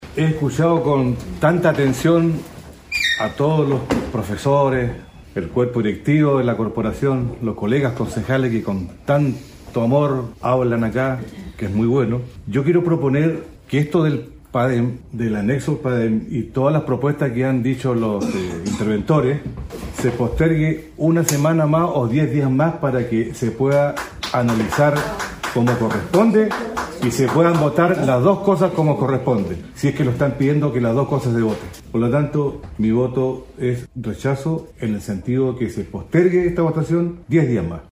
Por su parte, el Concejal Baltazar Elgueta, quien voto en contra de la aprobación, se refirió a los motivos que lo impulsaron a rechazar el Padem: